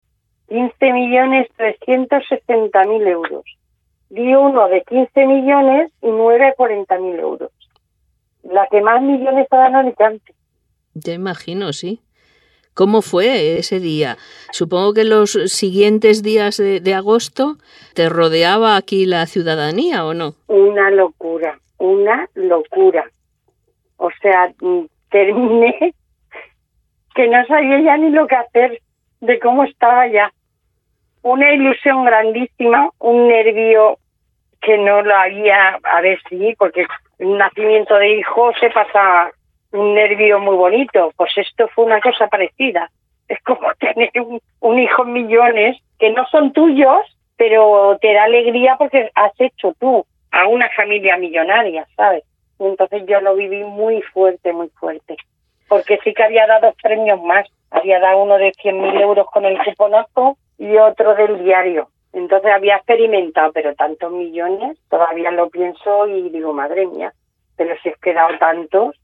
con sincera felicidad formato MP3 audio(1,32 MB).